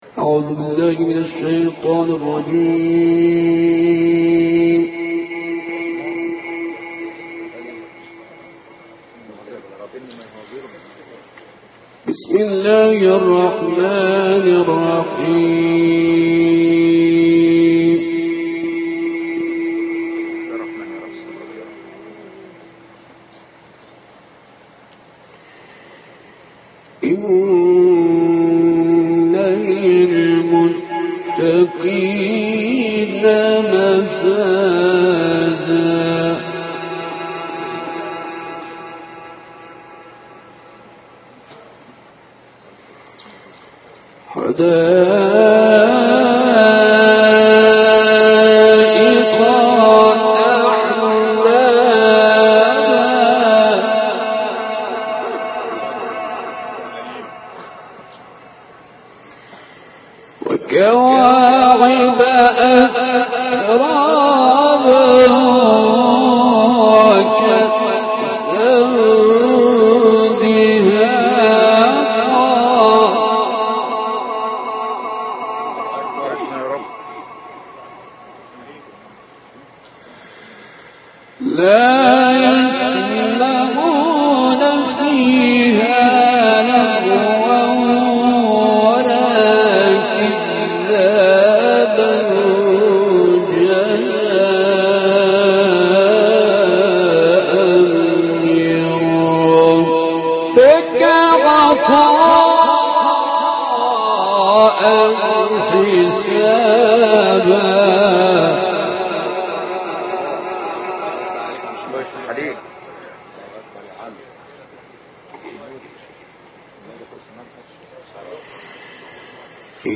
رعایت اصولی فنون مختلف در تلاوت